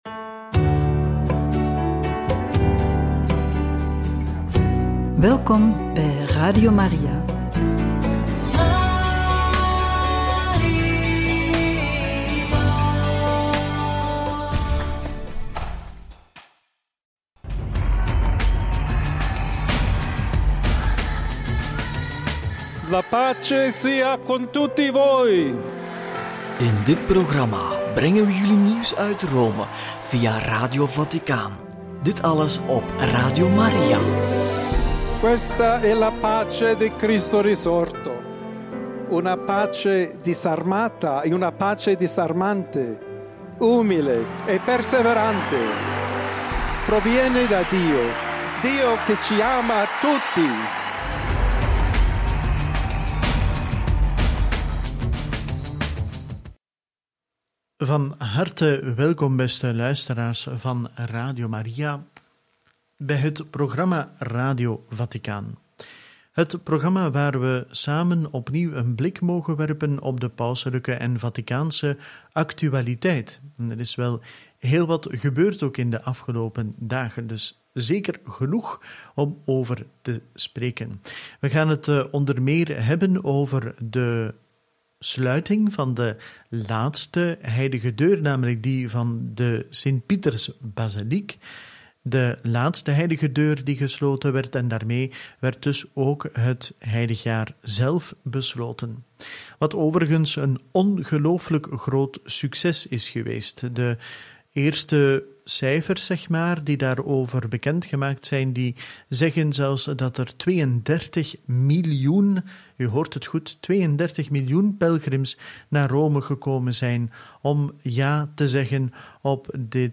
Paus Leo XIV sluit laatste Heilige Deur, einde Heilig Jaar van de hoop – Homilie Epifanie + Angelus – Catechese over Vaticanum II – Radio Maria